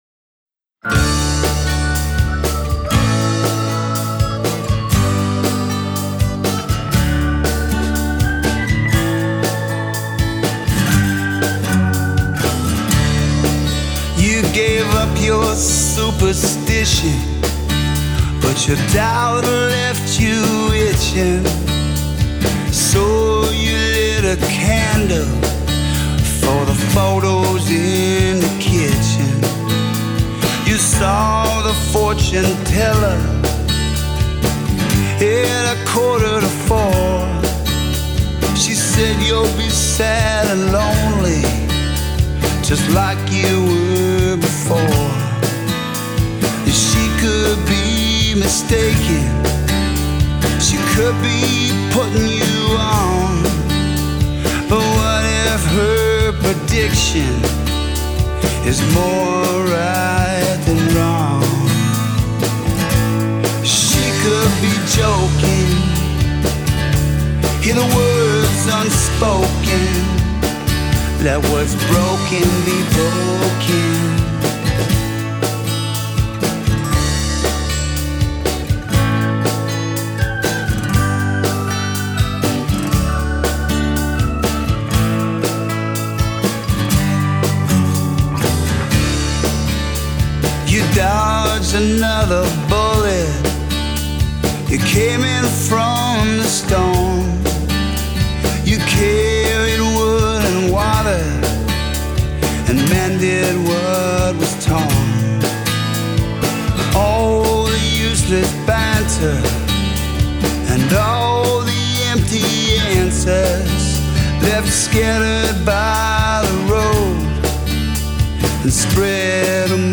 Singer, Songwriter, Guitarist